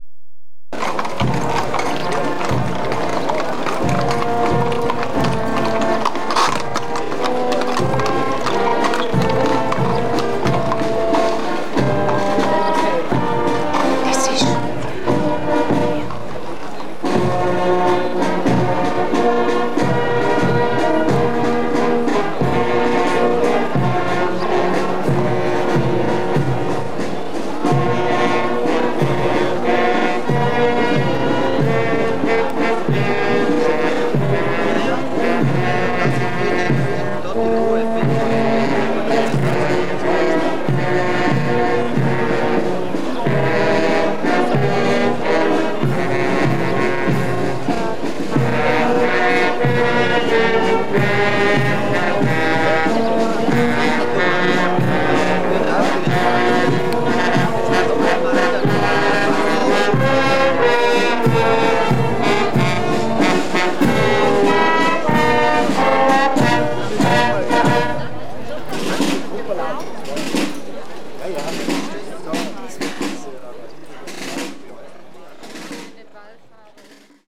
Prozessions Märsche
im Trio das bekannte Kirchenlied "Segne Du Maria"